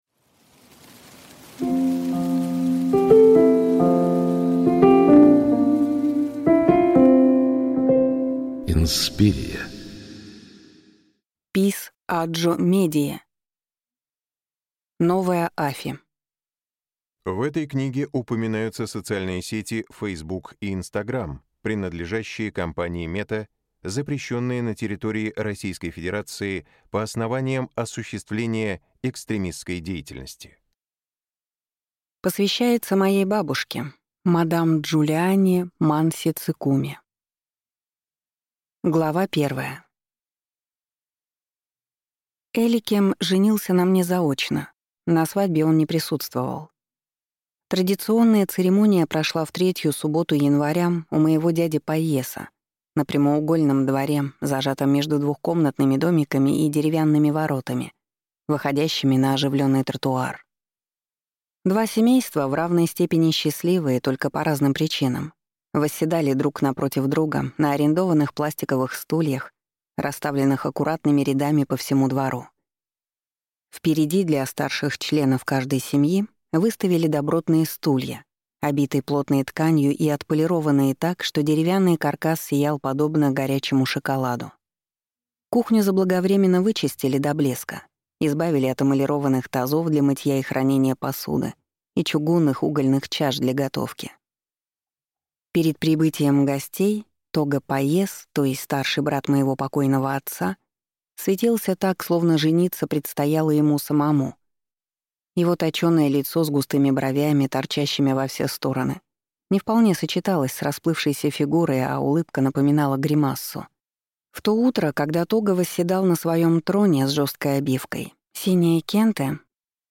Аудиокнига Новая Афи | Библиотека аудиокниг